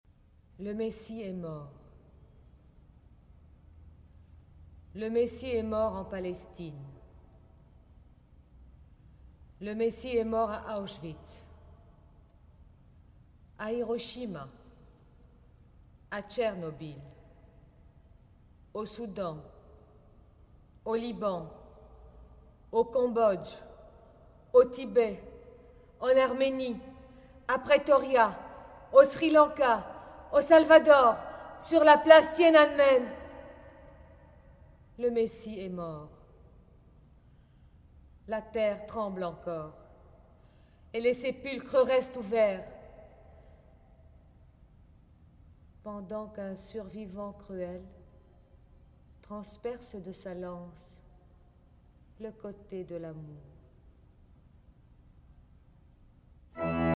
Disque compact accompagnant le livre